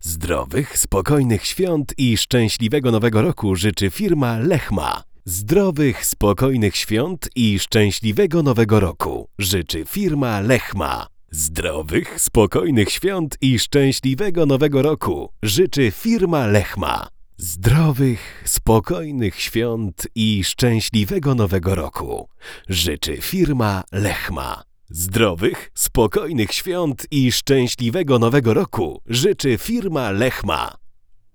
Ustawiamy klasyczną nerkę i jedziemy.
Brzmienie jest pełne, ale z odrobiną piasku. Klarowne, ale nie sterylne.